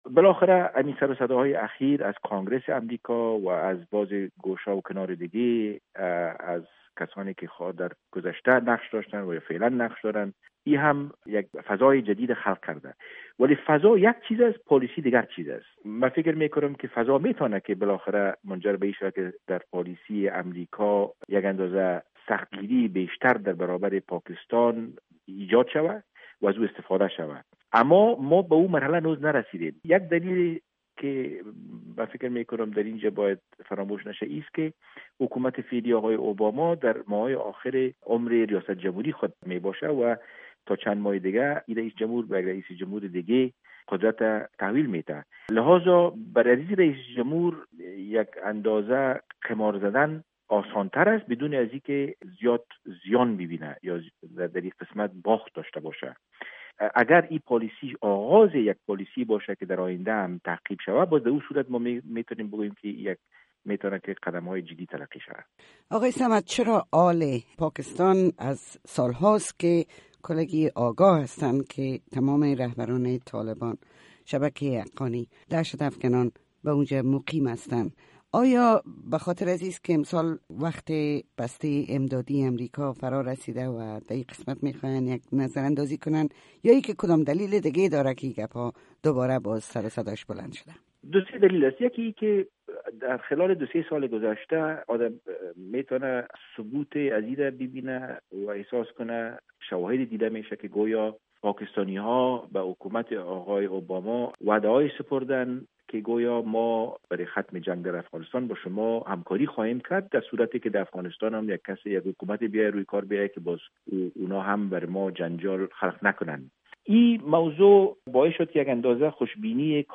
مصاحبه با عمر صمد، دیپلومات پیشین افغانستان در مورد روابط امریکا و پاکستان و تاثیر آن بر اوضاع در افغانستان